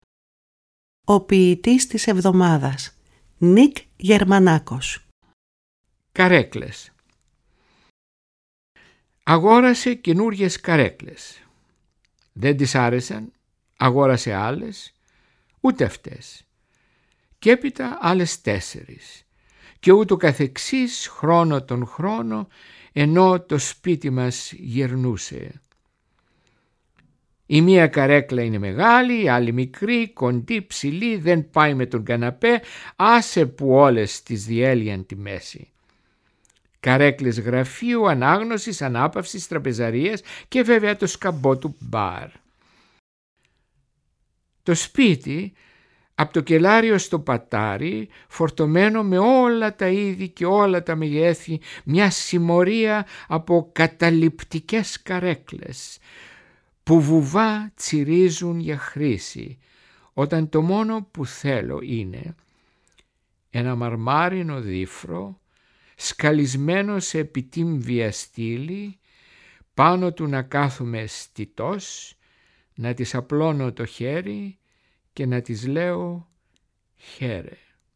Η ΦΩΝΗ ΤΗΣ ΕΛΛΑΔΑΣ, το ραδιόφωνο της ΕΡΤ που απευθύνεται στους Έλληνες όπου γης, με όχημα τη διάδοση, τη στήριξη, και την προβολή της ελληνικής γλώσσας και του ελληνικού πολιτισμού, εντάσσει και πάλι στο πρόγραμμά της τα αφιερωματικά δίλεπτα ποίησης με τίτλο
Οι ίδιοι οι ποιητές, καθώς και αγαπημένοι ηθοποιοί  επιμελούνται τις ραδιοφωνικές ερμηνείες. Παράλληλα τα ποιήματα «ντύνονται» με πρωτότυπη μουσική, που συνθέτουν και παίζουν στο στούντιο της Ελληνικής Ραδιοφωνίας οι μουσικοί της Ορχήστρας της ΕΡΤ, καθώς και με μουσικά κομμάτια αγαπημένων δημιουργών.